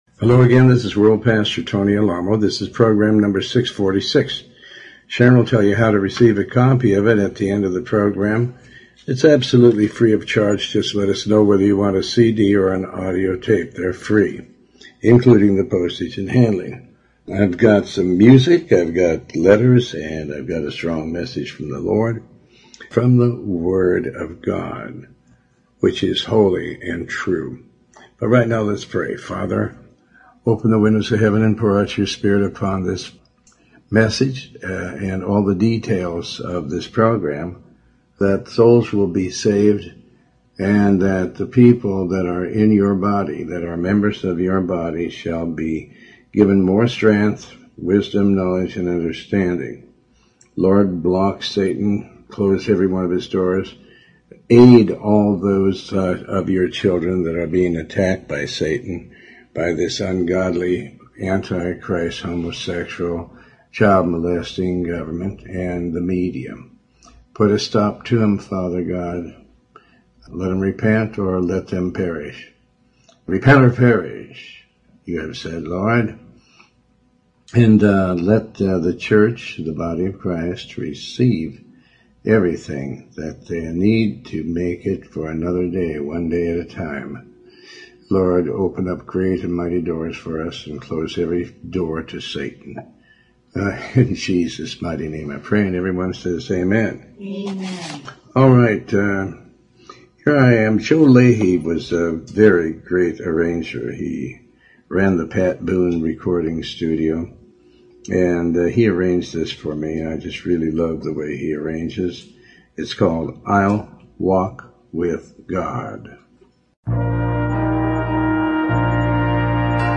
Talk Show Episode, Audio Podcast, Tony Alamo and The Bible tells us we must do that which is good.